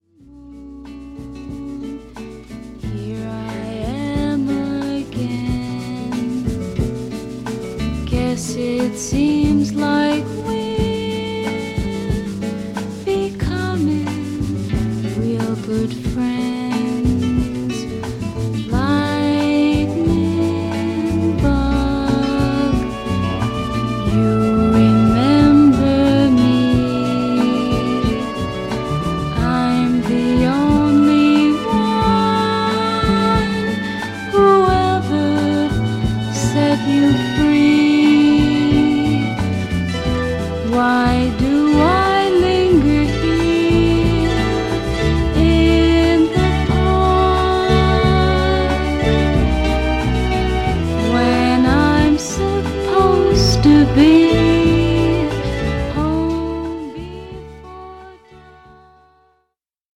どちらも見事に儚さや素朴さに溢れた美しい曲です。